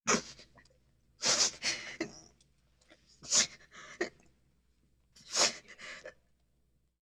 ES_Female Crying - SFX Producer.wav